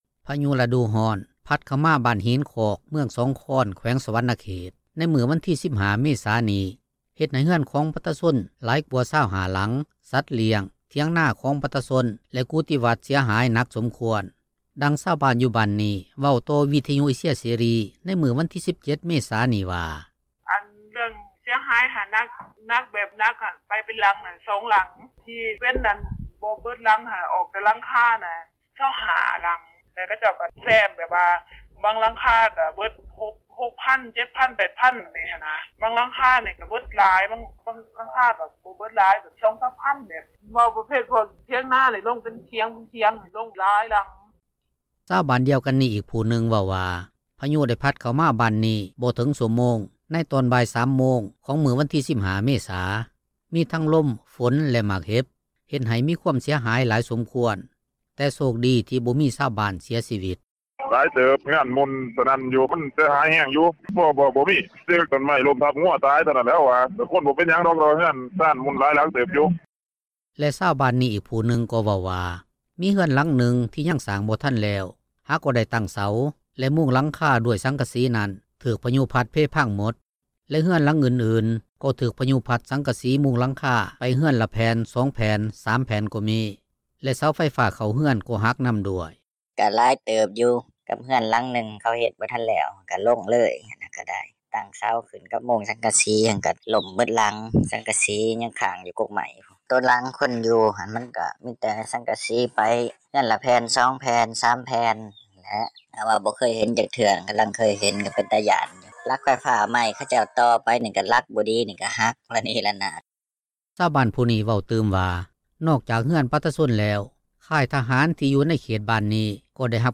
ດັ່ງຊາວບ້ານ ຢູ່ບ້ານນີ້ ເວົ້າຕໍ່ວິທຍຸ ເອເຊັຽເສຣີ ໃນມື້ວັນທີ 17 ເມສານີ້ວ່າ:
ດັ່ງເຈົ້າໜ້າທີ່ ບ້ານທີ່ກ່ຽວຂ້ອງ ກ່າວຕໍ່ວິທຍຸເອເຊັຽເສຣີ ໃນມື້ວັນທີ 17 ເມ ສານີ້ວ່າ: